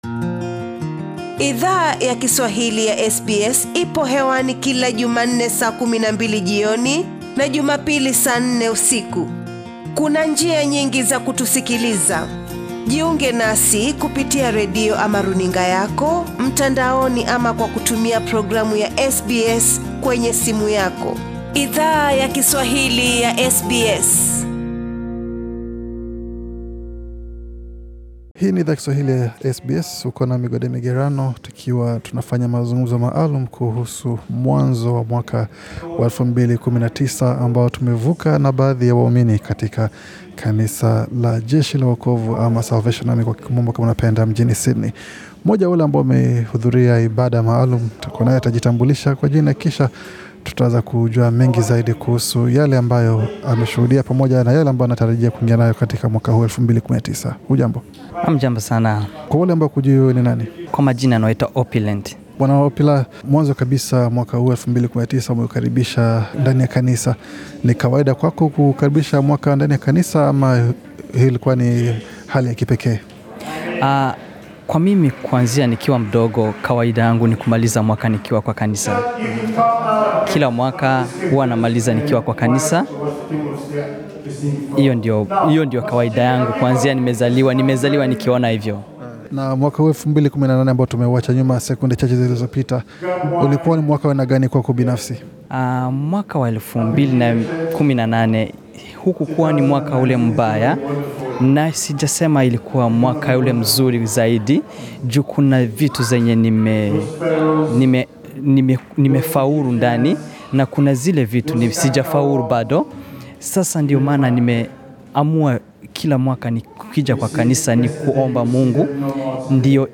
SBS Swahili ilijumuika na waumini katika ibada yakufunga mwaka wa 2018, katika kitongoji cha Auburn, NSW. Haya ndiyo waliyo tueleza usiku wa manane ulipofika.